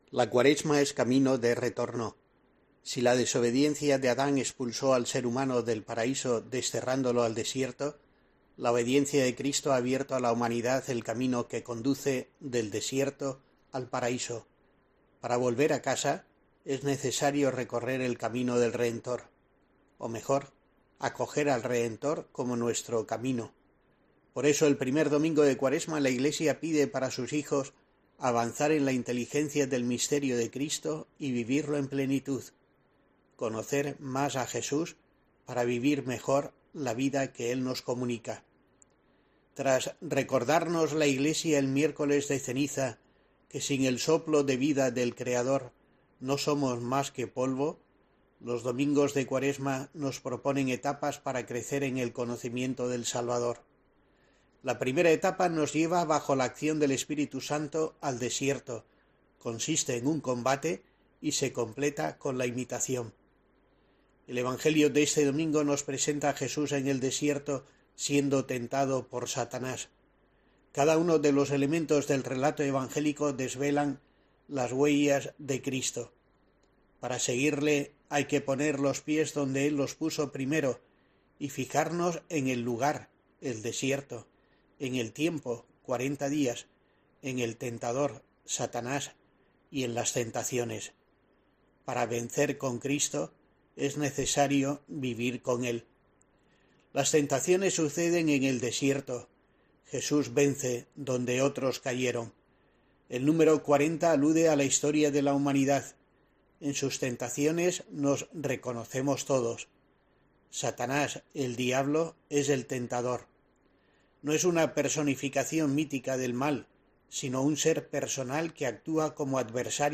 El obispo de Asidonia-Jerez subraya, en su reflexión semanal para la programación religiosa de COPE, el sentido del tiempo litúrgico que comienza